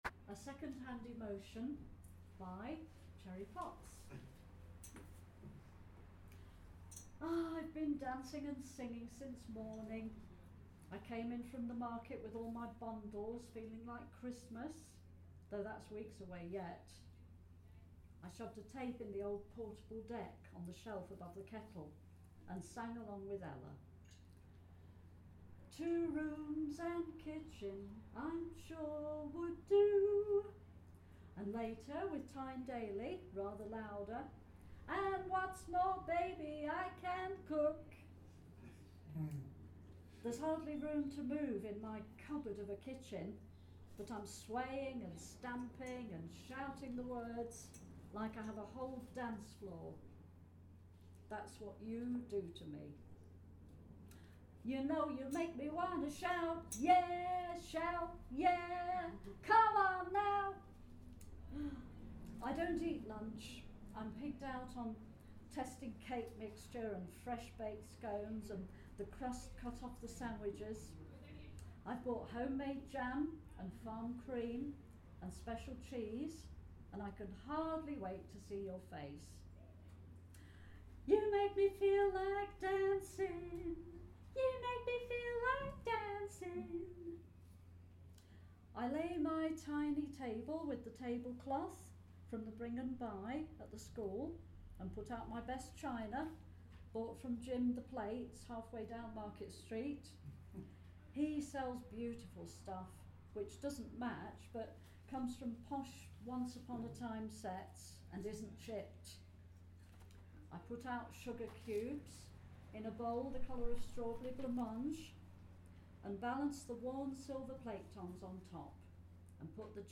reading (and singing – brave woman!)